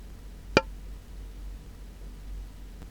• ■ 振動音を聞く事ができます。( 打音2回、約４秒 )
石膏ボードの打音 / wav +遮音シートの打音 / wav +制振材の打音 / wav
tap-sound-s.mp3